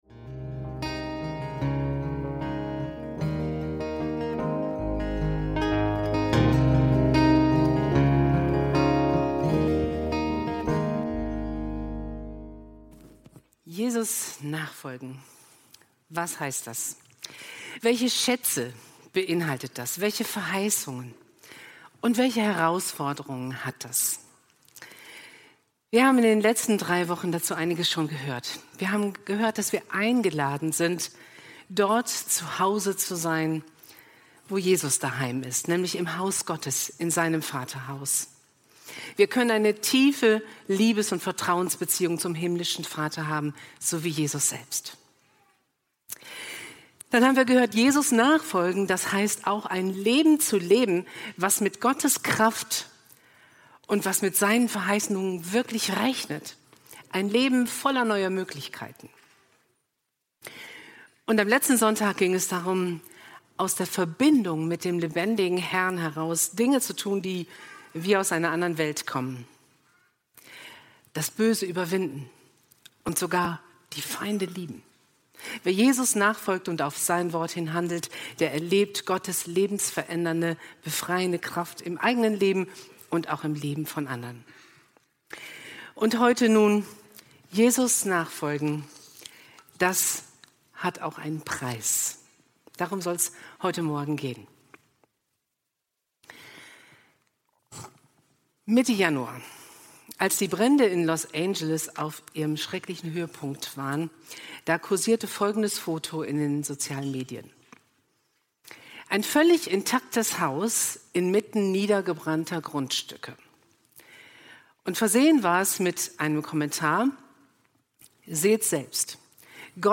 Der Preis "Nehmt auf euch mein Joch" – Predigt vom 23.02.2025